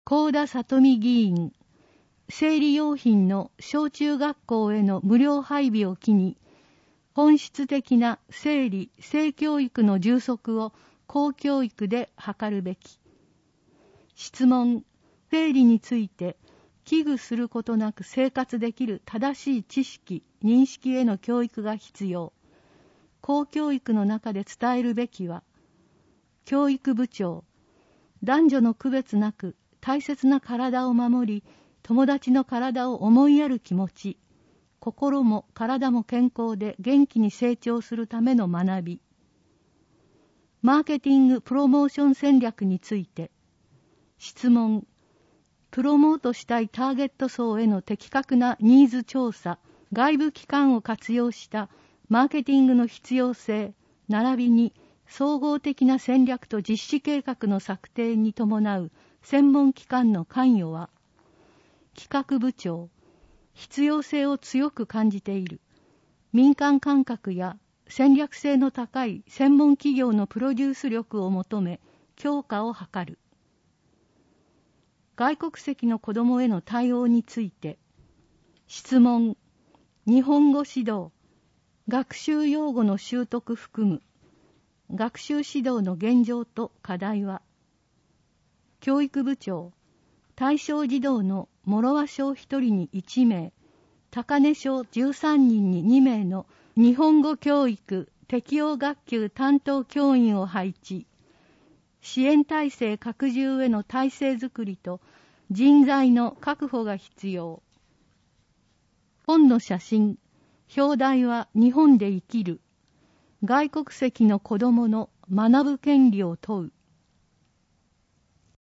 議会だより「ハイぎかいです」第140号音訳版（2021年8月1日発行）／東郷町